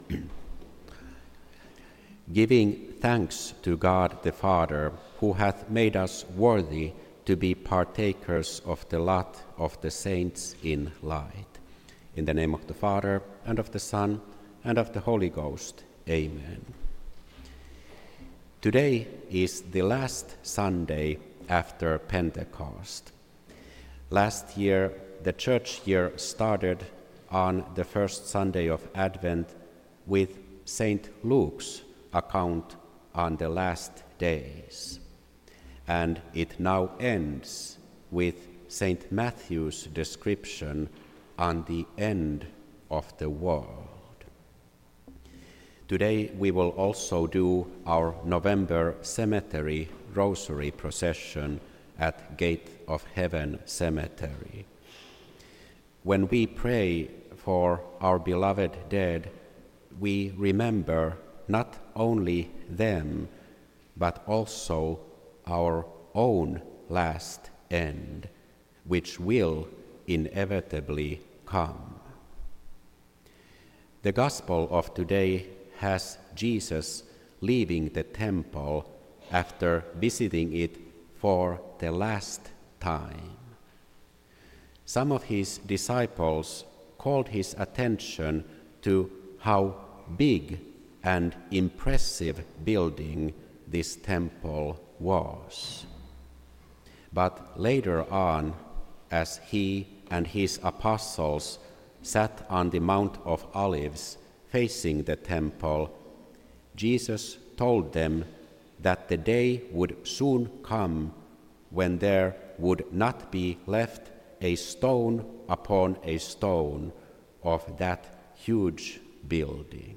This entry was posted on Sunday, November 23rd, 2025 at 4:27 pm and is filed under Sermons.